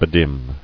[be·dim]